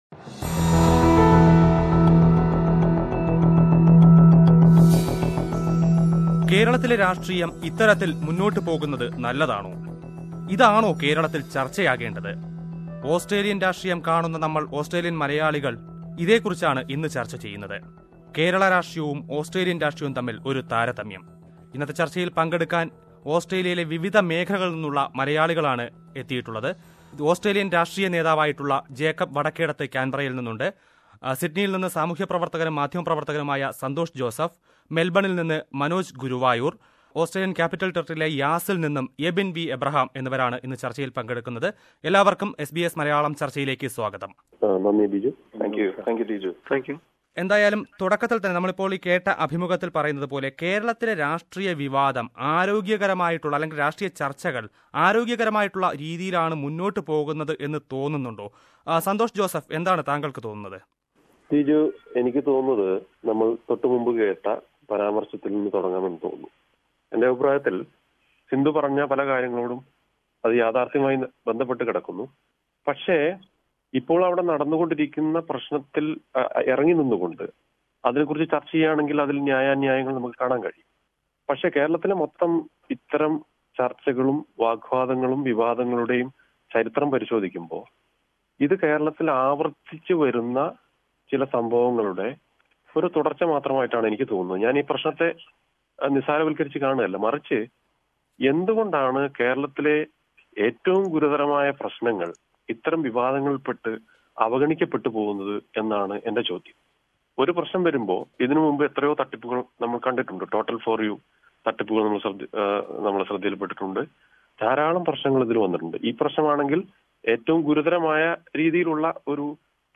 What do the Australian Malayalees think about the homeland politics? How do they compare it with the Australian politics? Listen to a panel discussion.